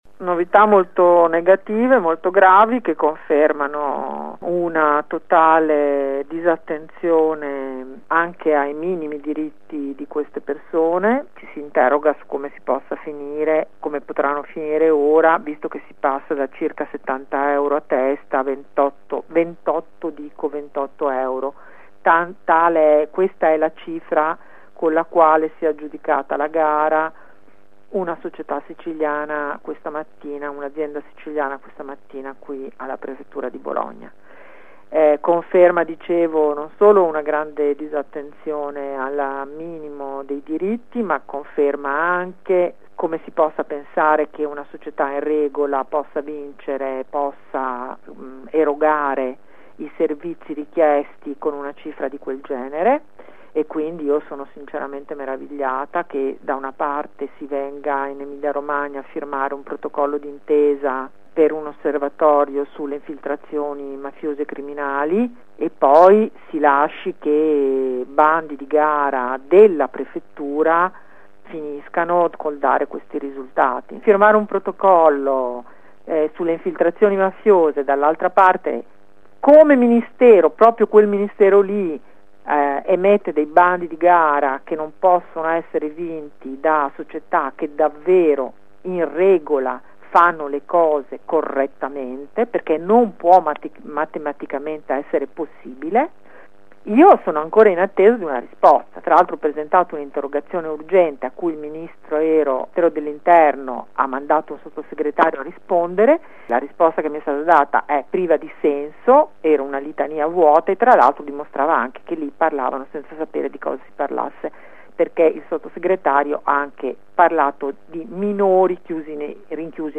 “Da una parte si firmano protocolli antimafia, dall’altra si emettono bandi che non possono essere vinti da aziende in regola, perché è matematicamente impossibile”, così la deputata del Pd Sandra Zampa critica ai nostri microfoni il comportamento della Prefettura, all’uscita dal centro di via Mattei per la campagna “LasciateCIE entrare“.